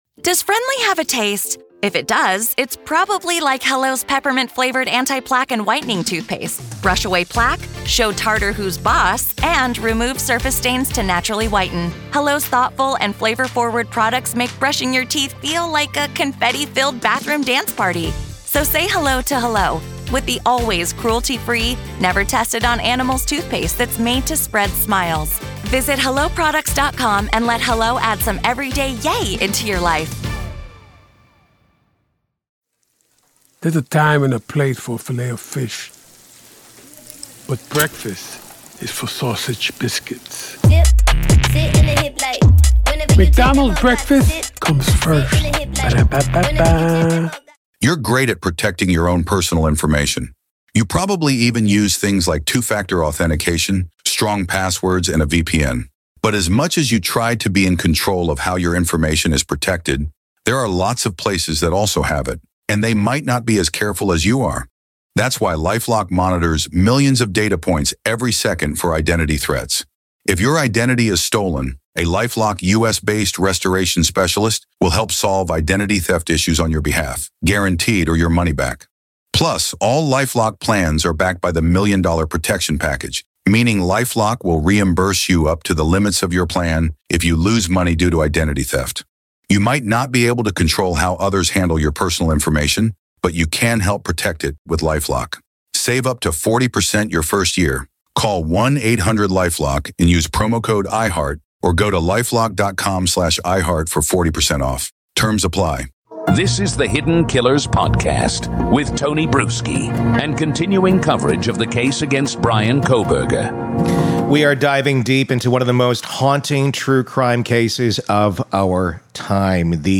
This is the full unfiltered conversation with Howard Blum , NYT bestselling author of When the Night Comes Falling , and it’s nothing short of explosive. In this episode, we dive into every angle of the Bryan Kohberger case — from the emotional fractures inside the Kohberger family to the courtroom strategies, from the psychological portrait of the accused to the uncomfortable questions still left unanswered.